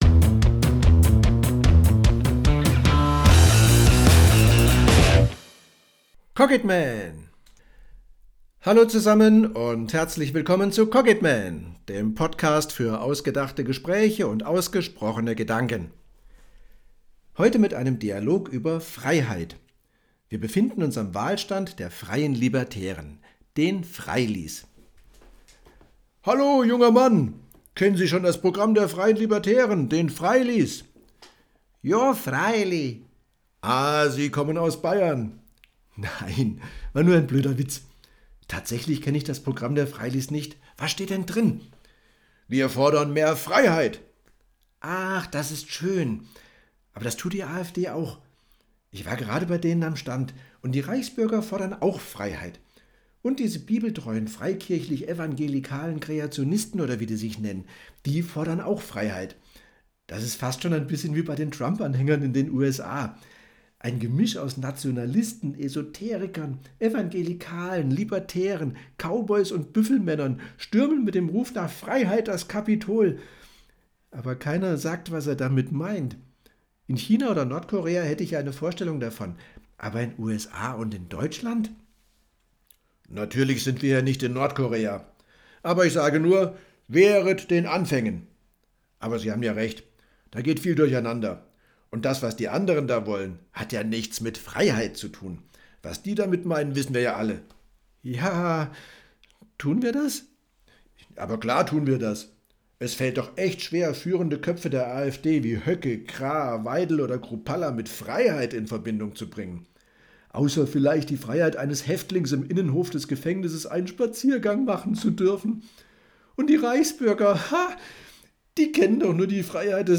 Zum Beispiel über einen Dialog.